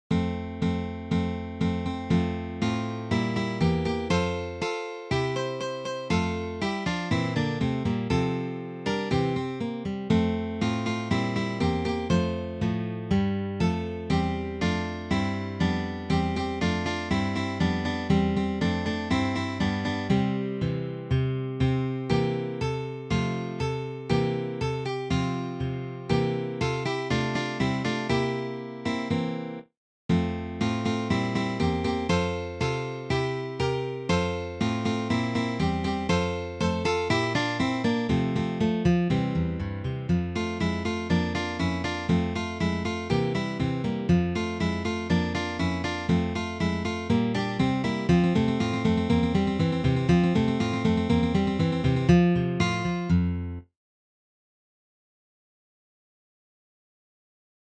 four guitars